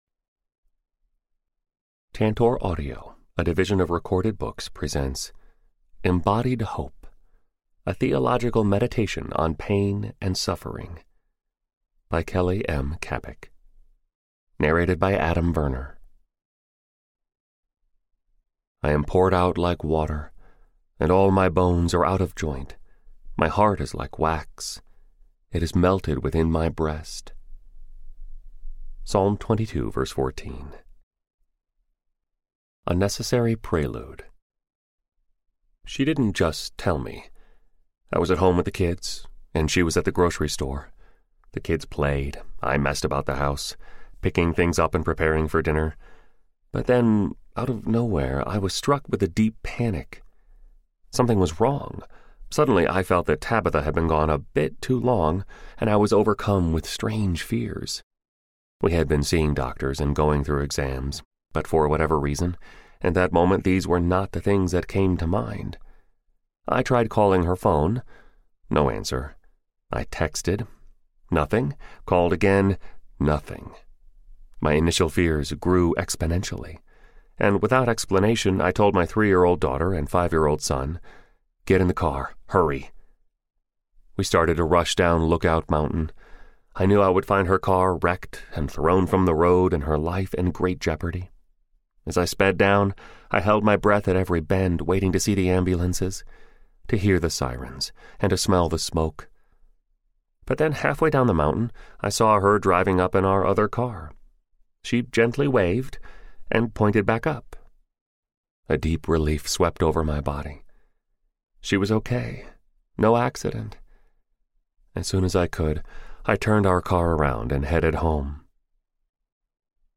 Embodied Hope Audiobook
Narrator